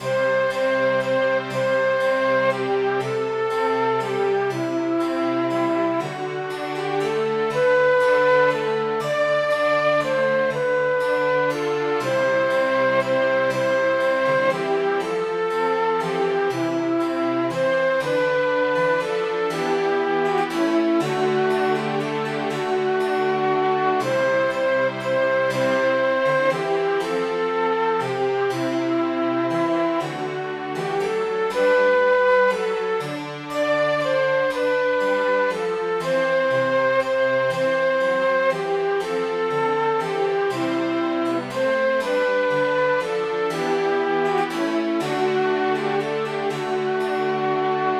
Midi File, Lyrics and Information to The Woods So Wild